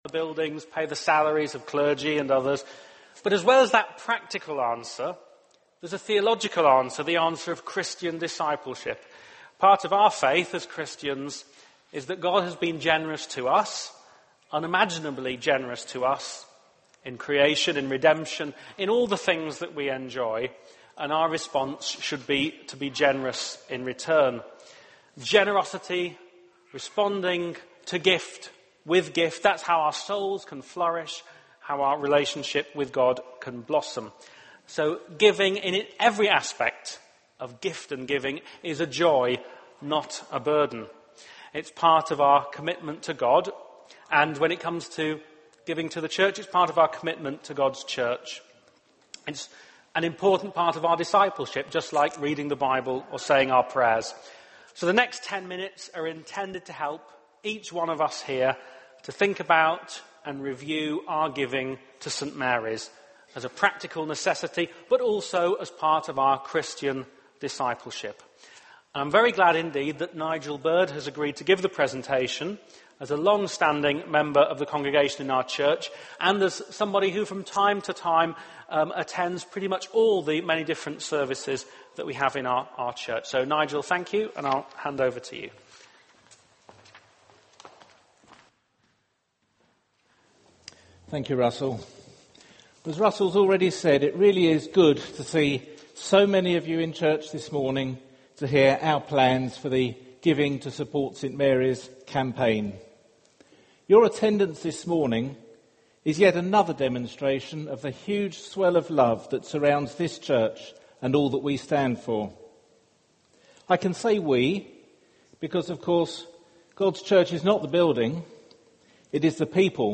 apologies the first minute of the recording is missing